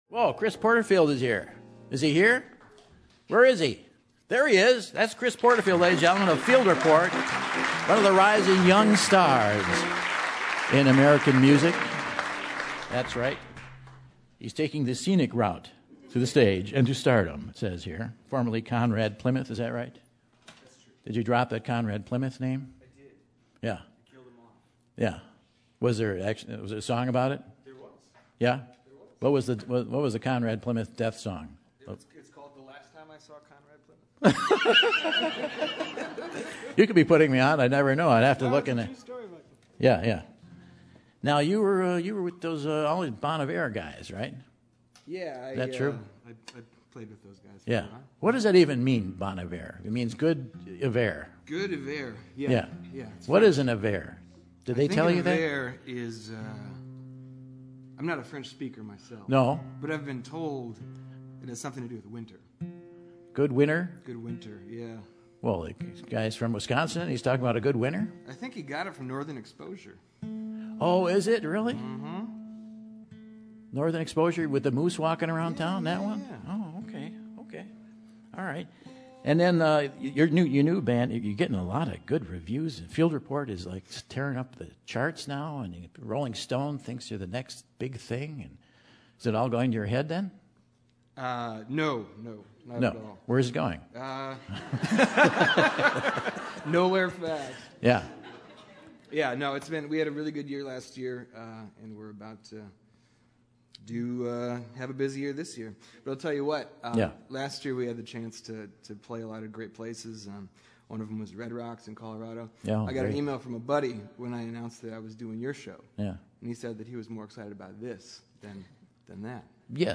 folk act